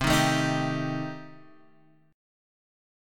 Cm6 Chord